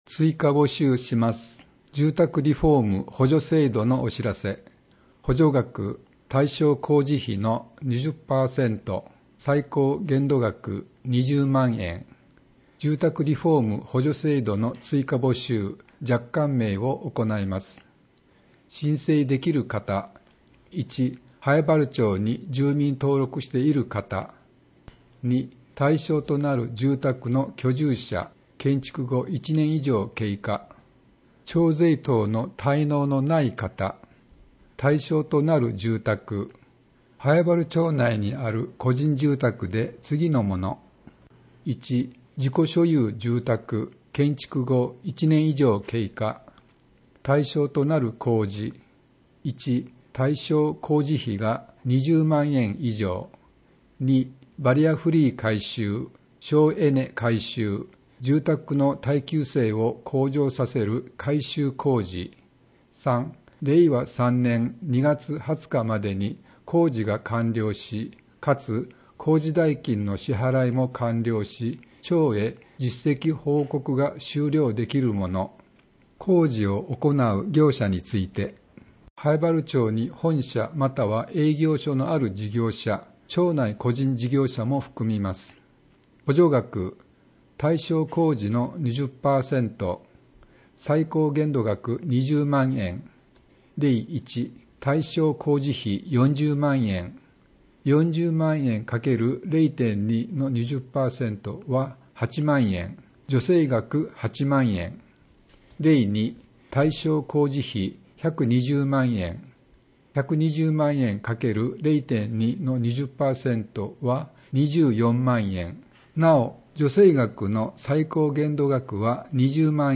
ページ 内容・見出し PDFファイル 声の広報